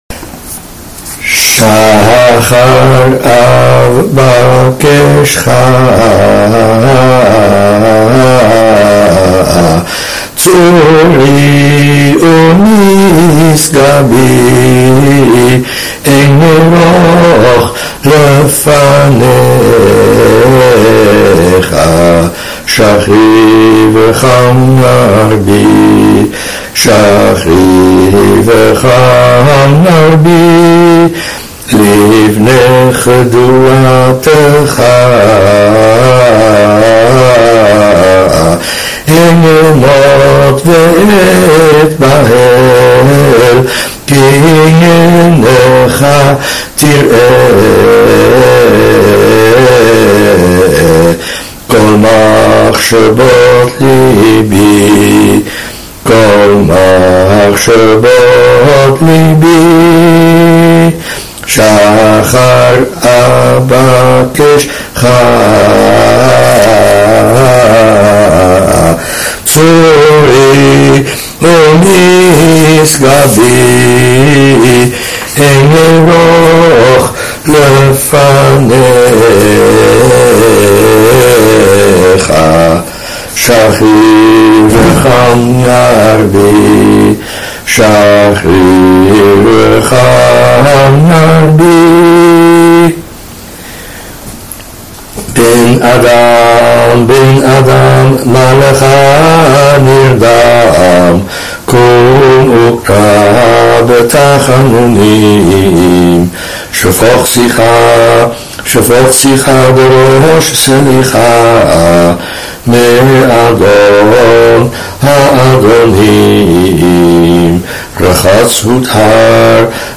Bakashot before morning selichot
Bakashot before selichot-part1.mp3